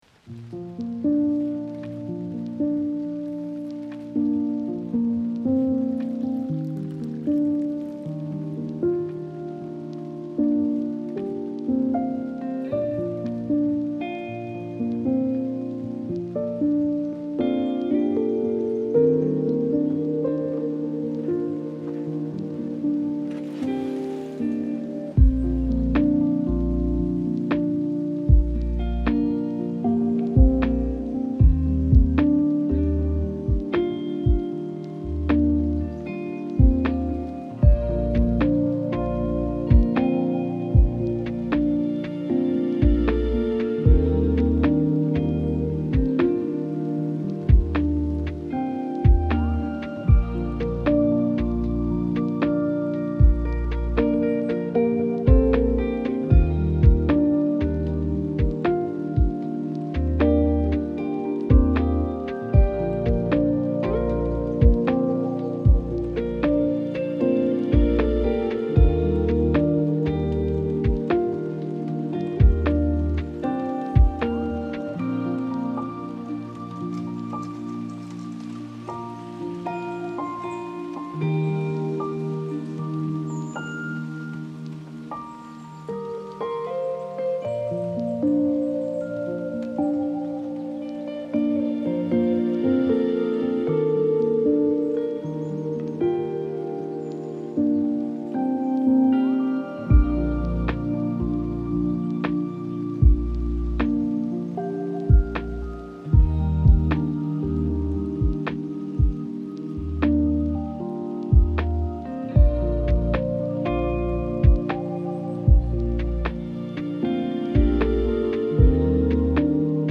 Умиротворяющая музыка для отдыха без ап